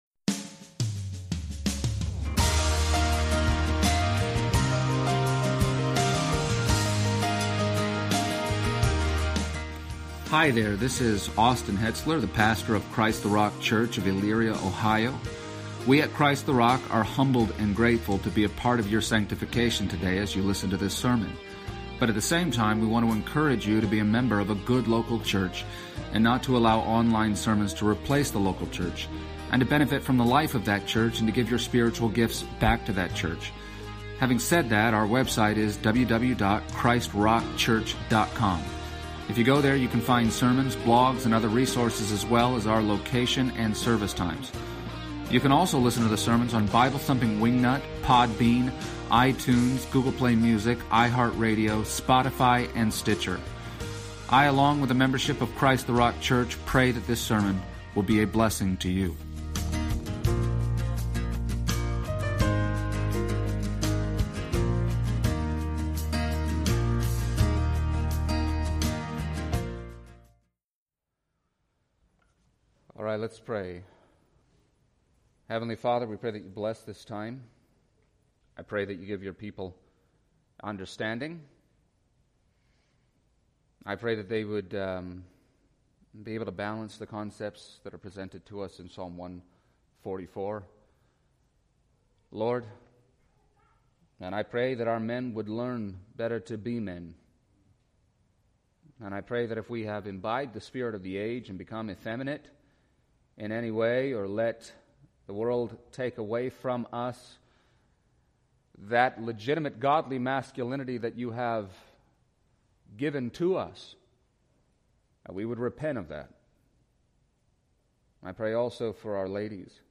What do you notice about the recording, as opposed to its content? Series: Sunday Service Passage: Psalm 144:1-15 Service Type: Sunday Morning